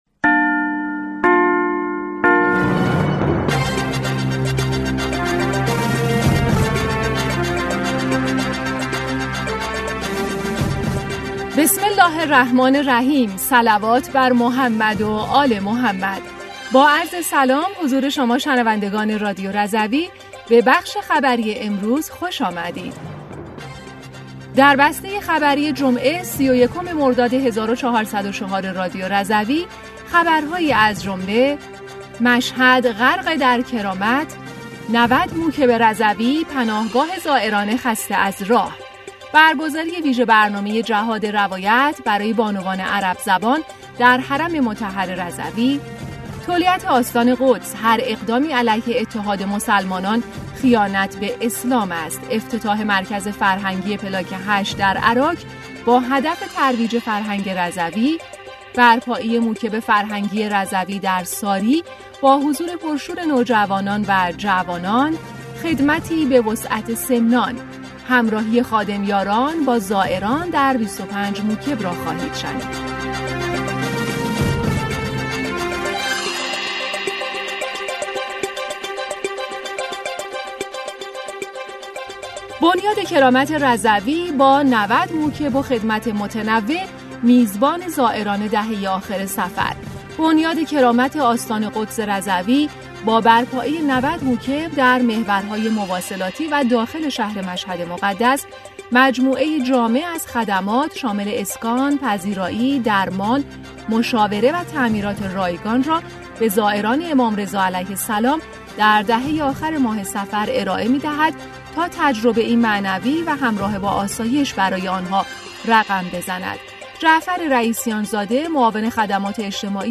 در بسته خبری جمعه ۳۱ مرداد ۱۴۰۴ رادیو رضوی خبرهایی از جمله، مشهد غرق در کرامت؛ ۹۰ موکب رضوی پناهگاه زائران خسته از راه، برگزاری ویژه‌برنامه «جهاد روایت» برای بانوان عرب‌زبان در حرم مطهر رضوی، سخنرانی تولیت آستان قدس در همایش پیامبر اعظم، افتتاح مرکز فرهنگی “پلاک ۸” در اراک باهدف ترویج فرهنگ رضوی، برپایی موکب فرهنگی رضوی در ساری با حضور پرشور نوجوانان و جوانان و خدمتی به وسعت سمنان؛ همراهی خادم‌…